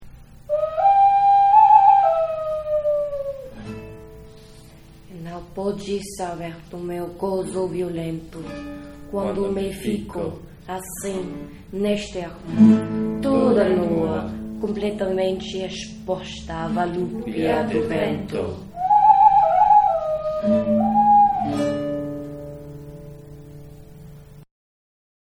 Our most multilingual poem-recording session ever. Unmastered, tracks just thrown together–but still sounding awesome.